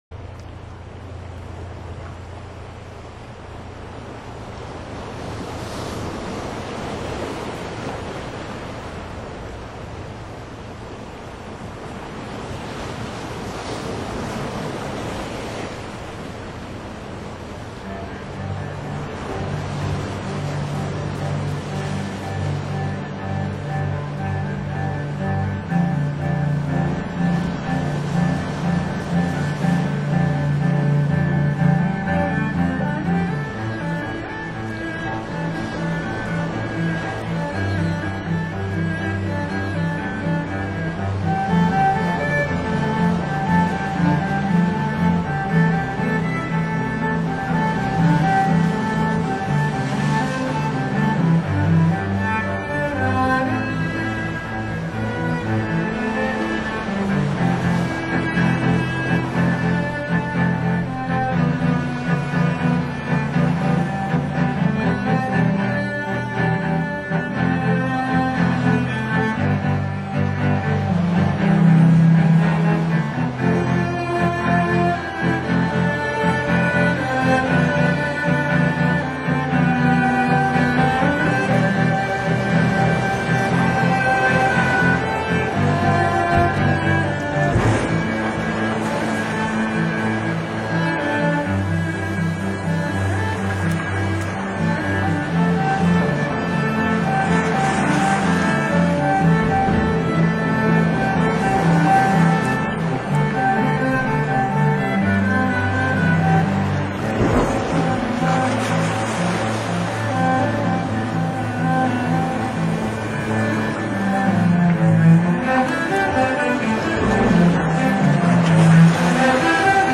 台湾沿海实地录音，临场海浪声绝无仅有。
大提琴
大提琴的低沉音色，弥漫着奇诡的情调。